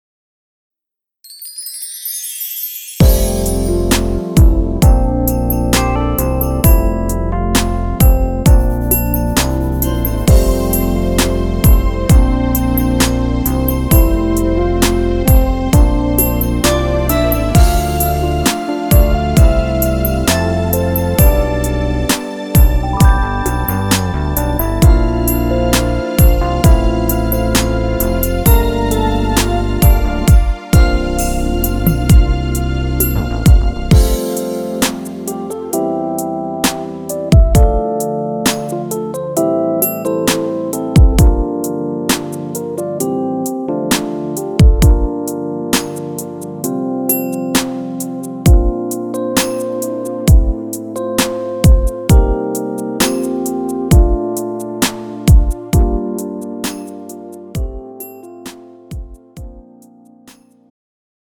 장르 축가 구분 Pro MR